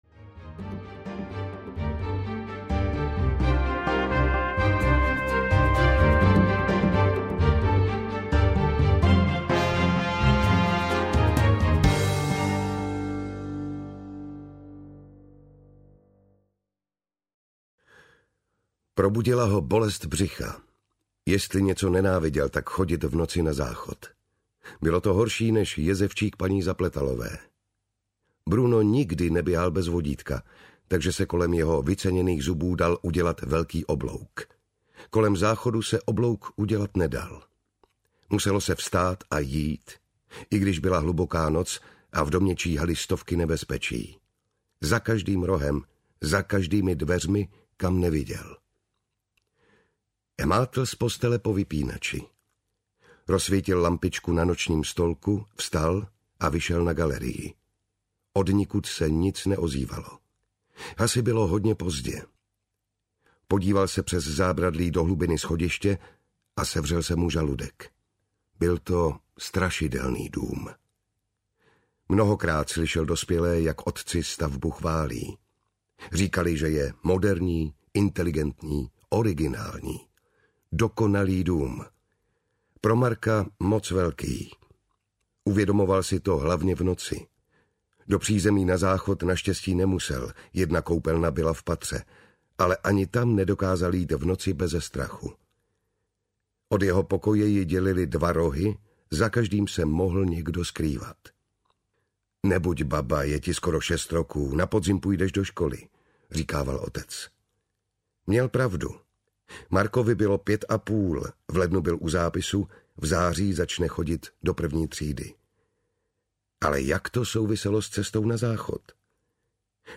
Muž na dně audiokniha
Ukázka z knihy
• InterpretJan Šťastný
muz-na-dne-audiokniha